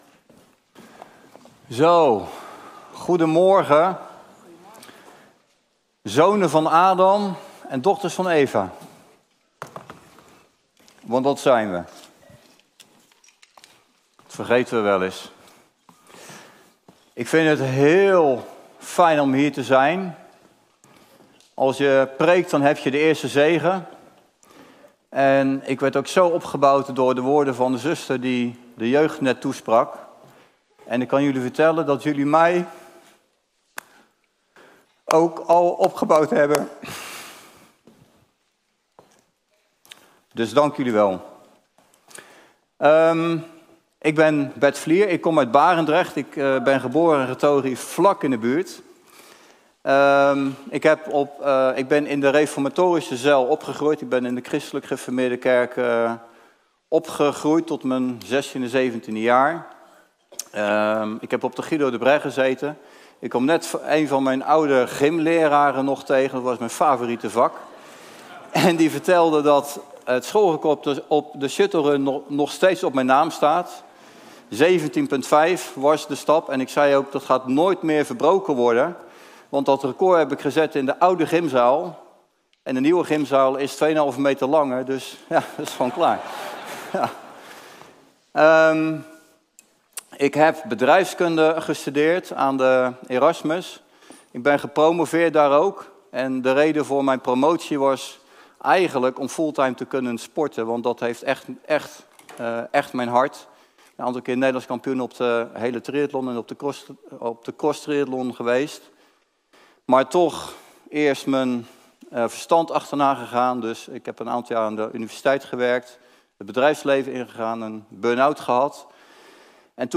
Passage: Psalm 119:63 Soort Dienst: Reguliere dienst « De gehoorzaamheid van de één opent de deur voor de gehoorzaamheid van een ander Wie zeg jij dat Ik ben?